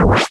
BW2_Cancel.WAV